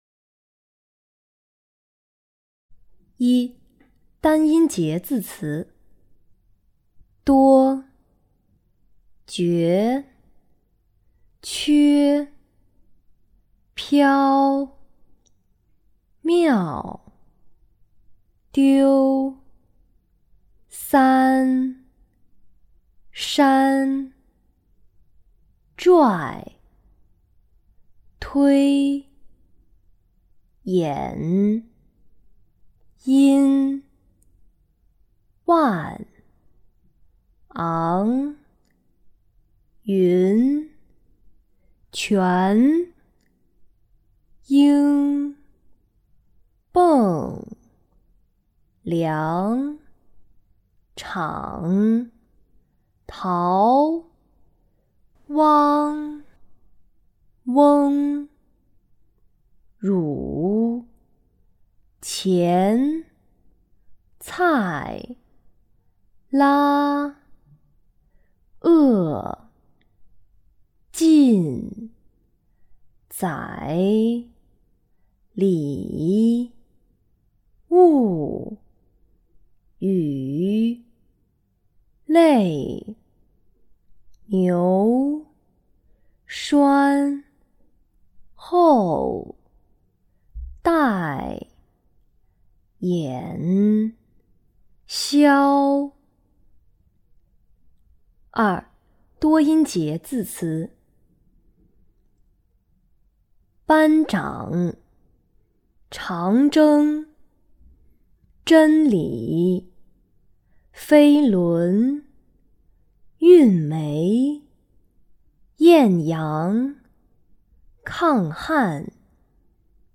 领读课件
单音节字词
多音节字词